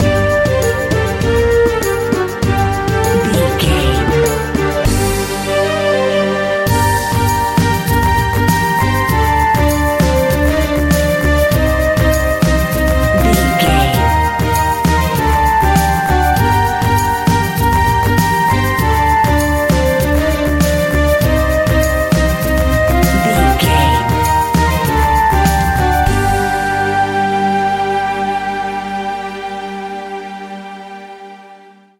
Aeolian/Minor
percussion
congas
bongos
kora
djembe
kalimba